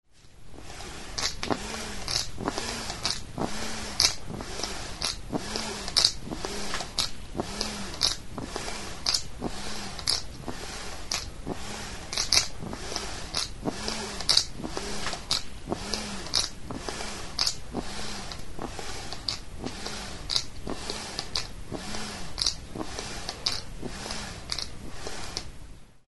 Aerophones -> Free-vibrating
Recorded with this music instrument.
Lau zuloko plastikozko botoi beltza da, zuloetatik pasatzen den sokatxo batekin.